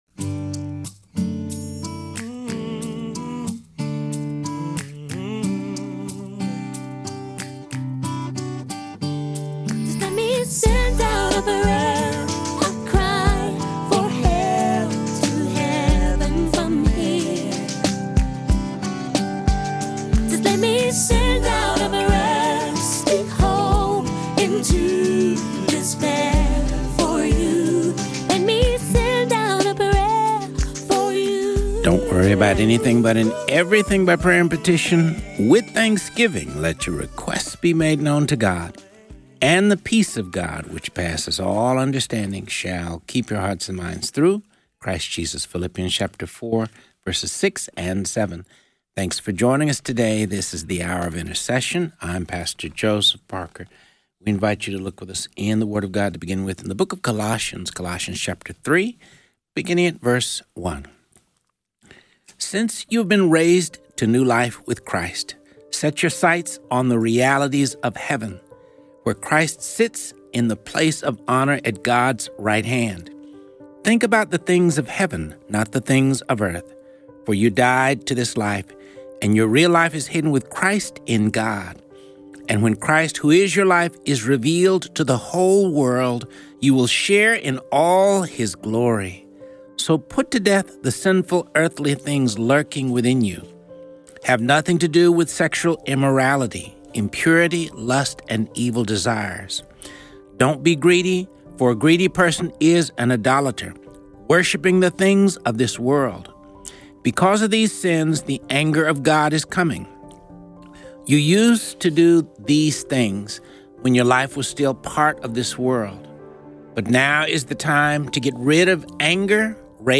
This show covers topics of prayer, intercession, the Word of God and features interviews with pastors and religious leaders.